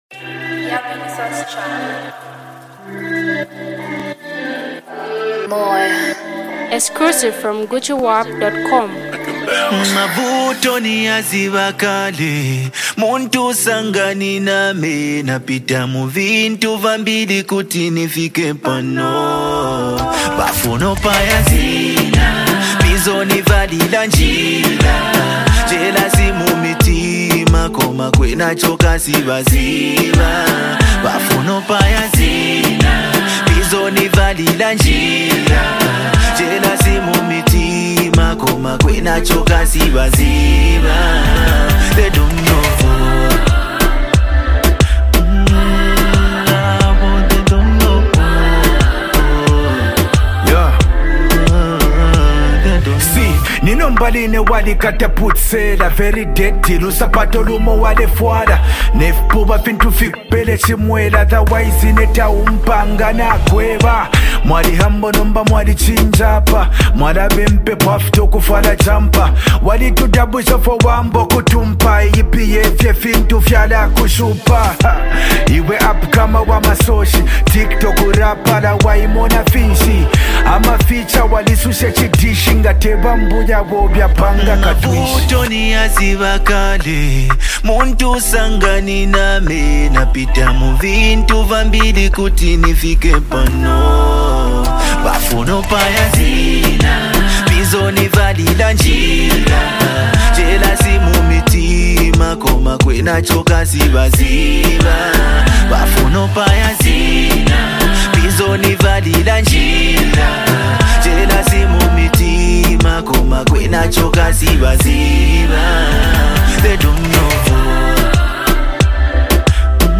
slick rhymes and banging beats